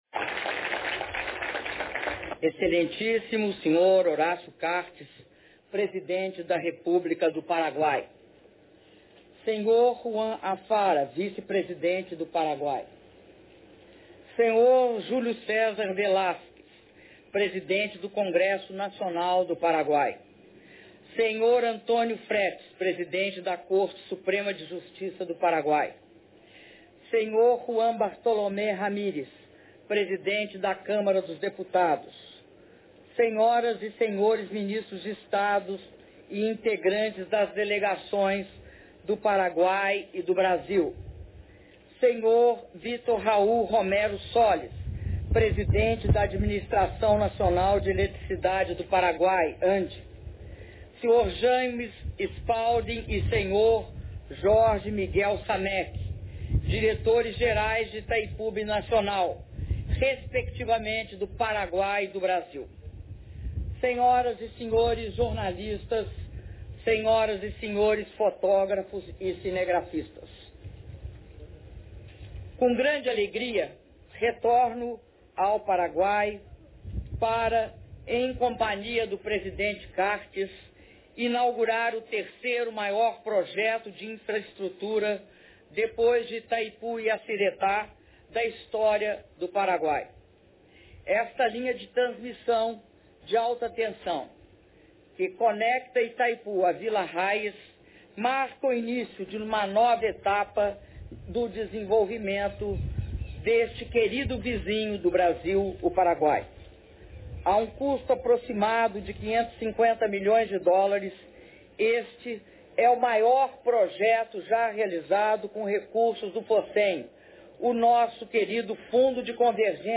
Áudio do discurso da Presidenta da República, Dilma Rousseff, na cerimônia de inauguração conjunta da Linha de 500 kV entre Villa Hayes e a subestação de energia da margem direita de Itaipu Binacional - Hernandárias/Paraguai